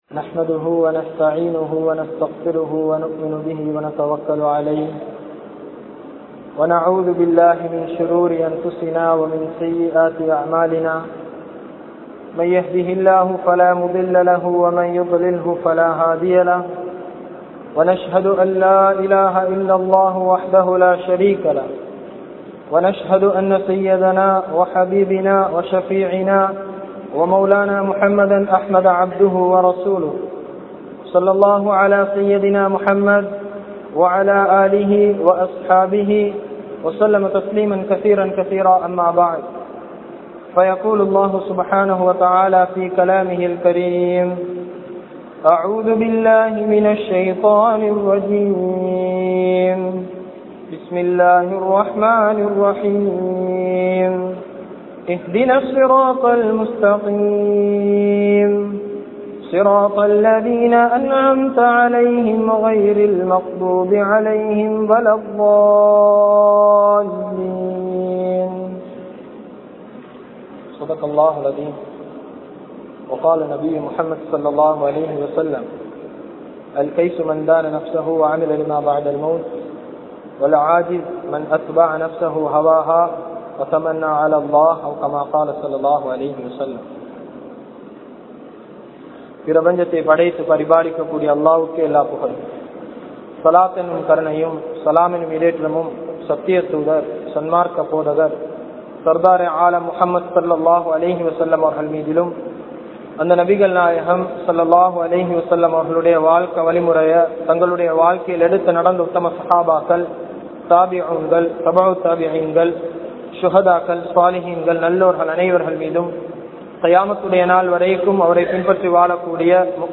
Islamiya Vaalifarhalin Mun Maathiri (இஸ்லாமிய வாலிபர்களின் முன்மாதிரி) | Audio Bayans | All Ceylon Muslim Youth Community | Addalaichenai